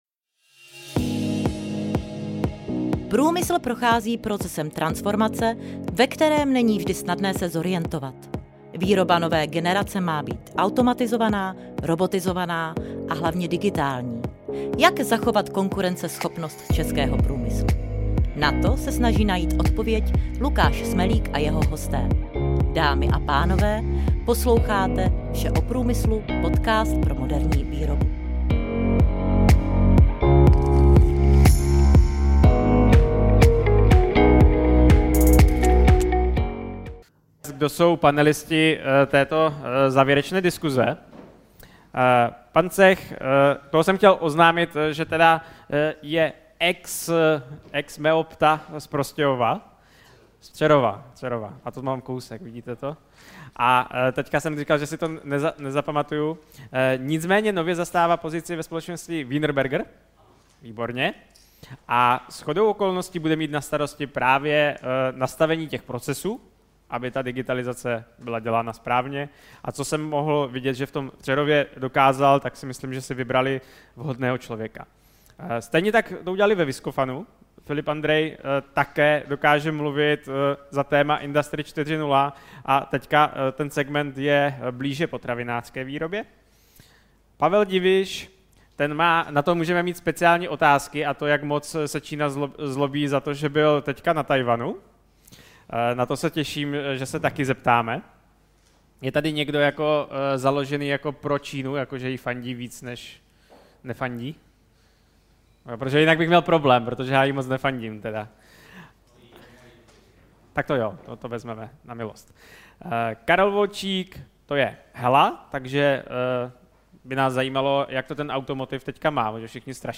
Speciální epizoda se vrací letos naposled ke konferenci Digitální výroba a přináší záznam z panelové diskuze odborníků na digitalizaci praxí ostřílených.